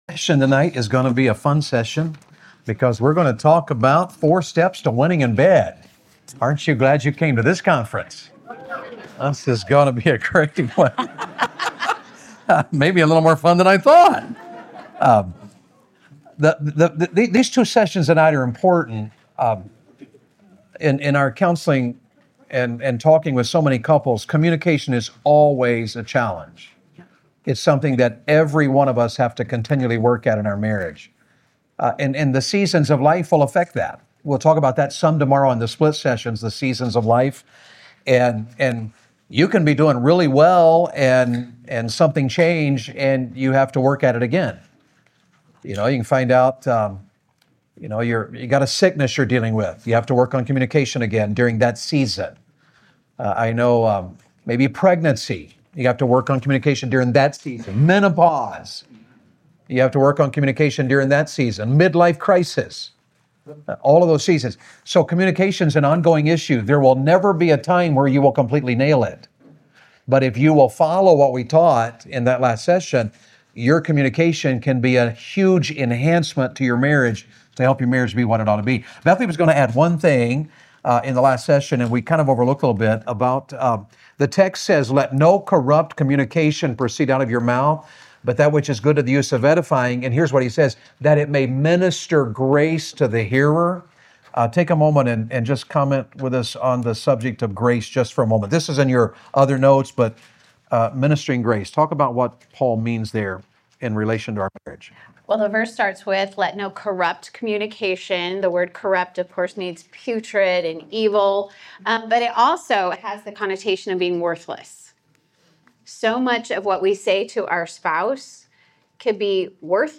Marriage Conference 2026
Guest Speaker